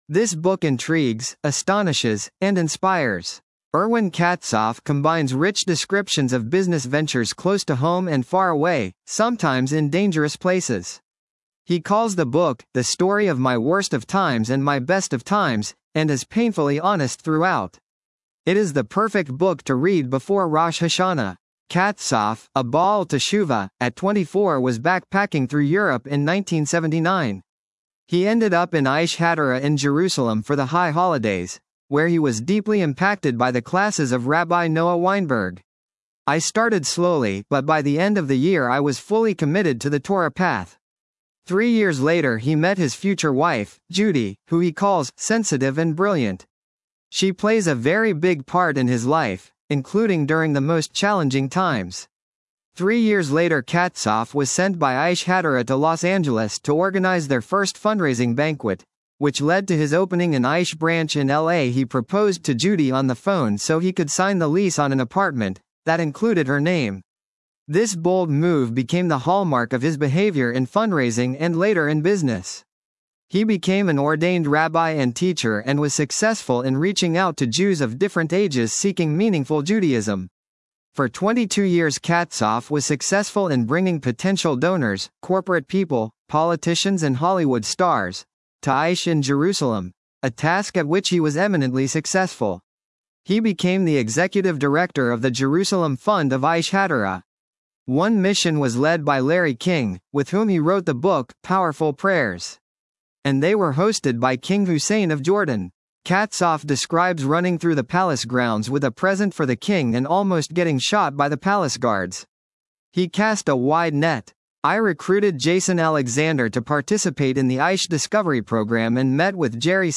Book Review and Interview: LIVING DANGEROUSLY, My Struggle to Get Rich Without Losing my Soul